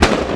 ExhaustFire1.wav